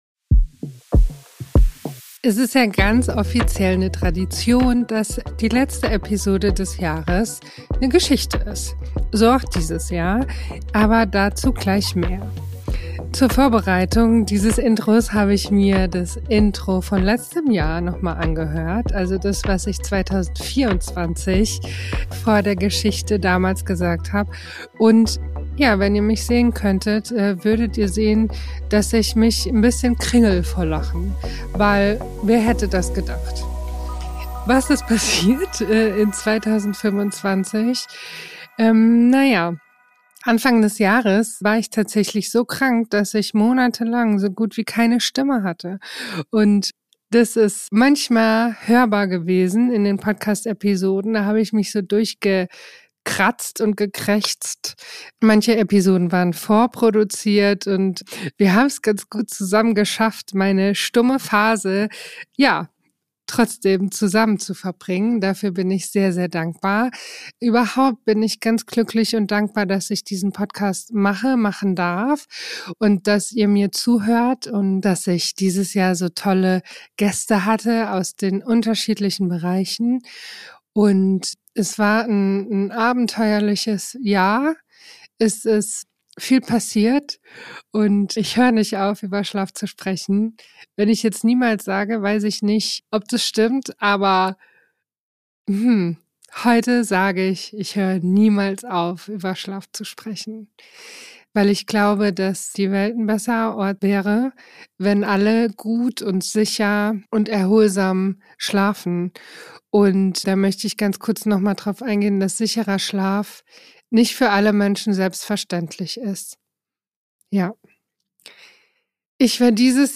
Es gibt eine Tradition hier im Podcast: Die Episode vor Weihnachten ist eine Geschichte.